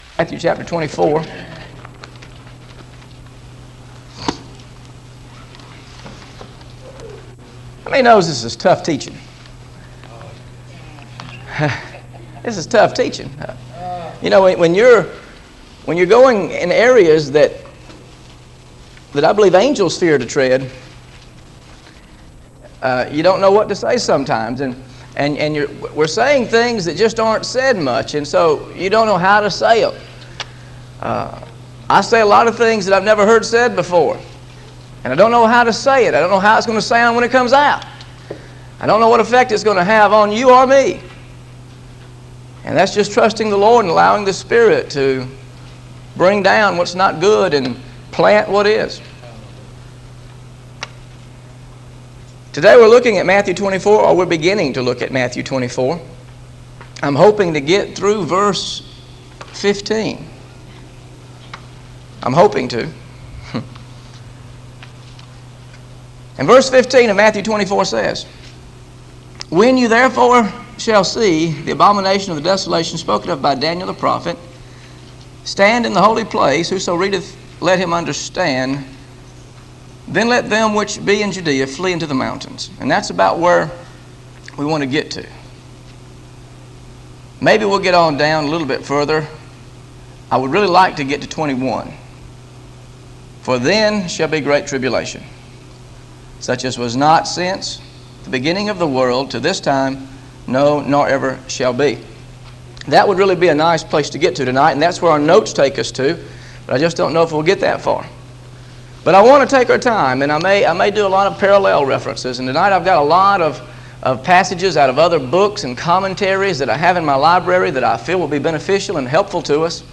GOSPEL OF MATTHEW BIBLE STUDY SERIES This study of Matthew: Matthew 24 Verses 1-5: How to Discern the Signs of the Times is part of a verse-by-verse teaching series through the Gospel of Matthew.